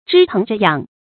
知疼著癢 注音： ㄓㄧ ㄊㄥˊ ㄓㄠˊ ㄧㄤˇ 讀音讀法： 意思解釋： 形容對親人的關懷、體貼。